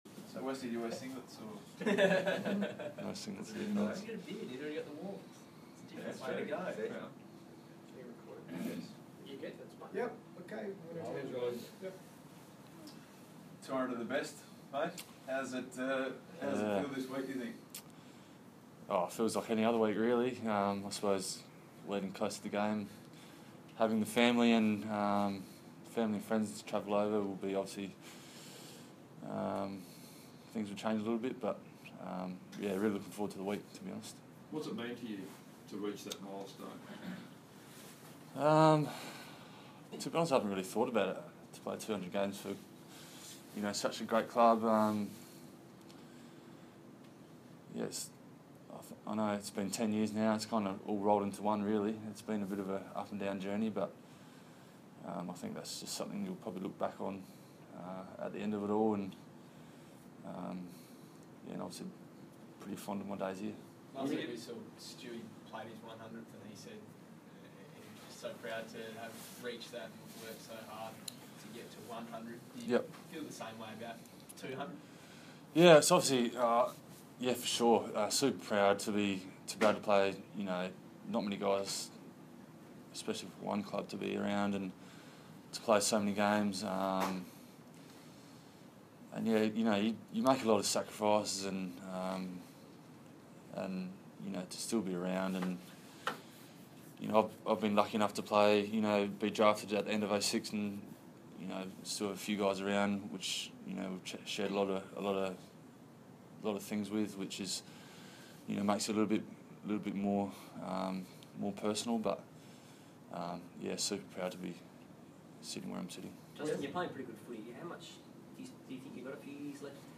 Justin Westhoff press conference, Monday, 1 August, 2016